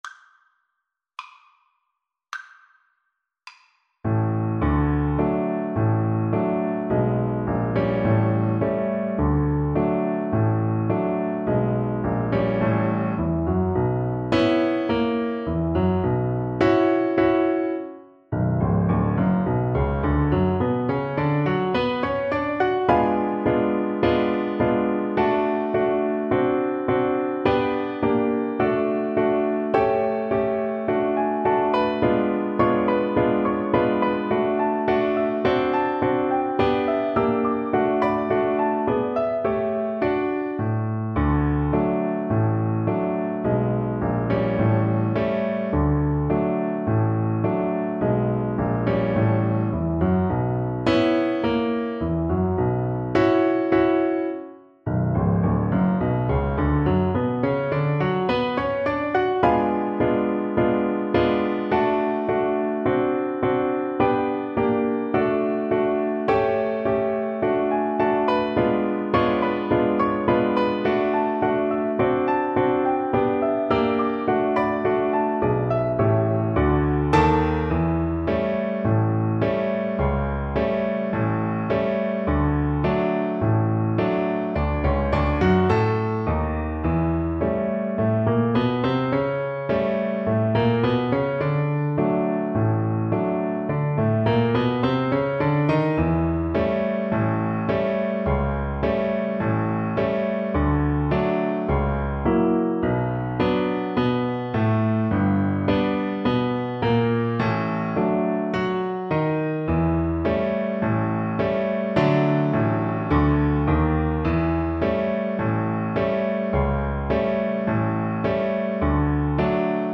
2/4 (View more 2/4 Music)
Slow march tempo. = 74 Slow march tempo
Jazz (View more Jazz Violin Music)
Ragtime Music for Violin